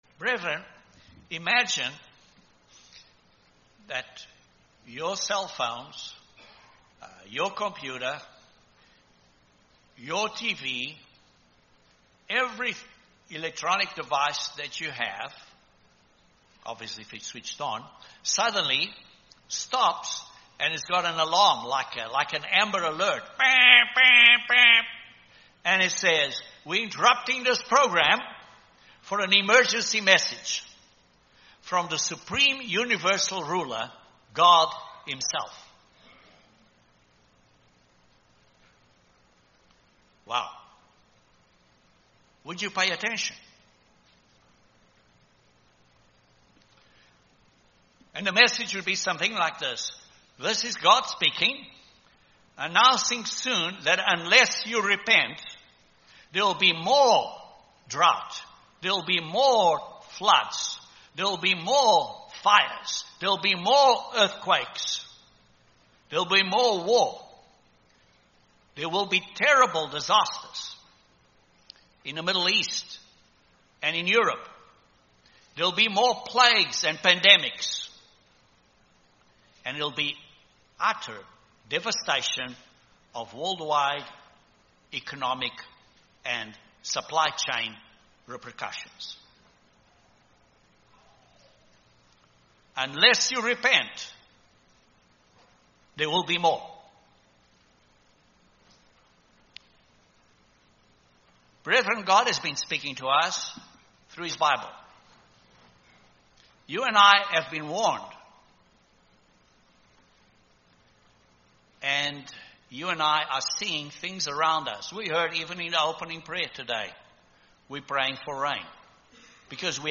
This sermon looks at what God says to the world and to us as members of His Church.